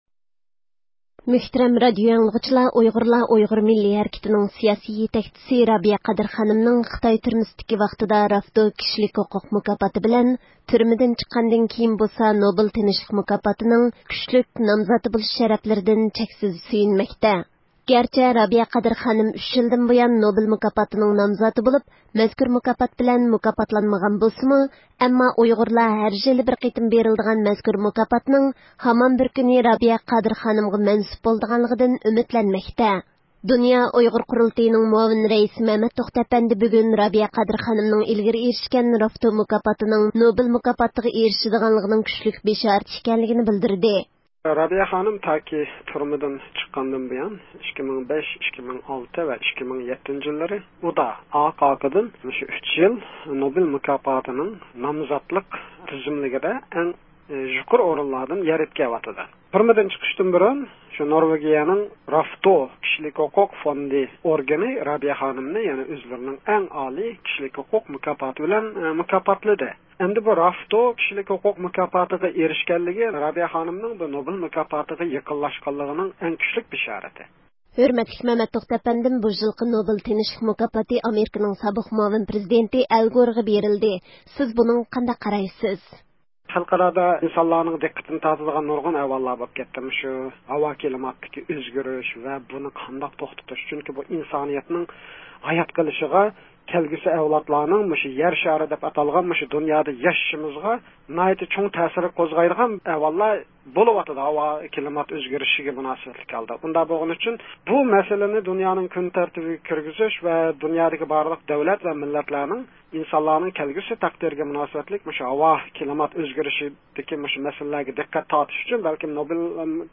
ئۇيغۇر سىياسىي پائالىيەتچىلىرى بىلەن ئېلىپ بارغان سۆھبىتىنى ئاڭلايسىز.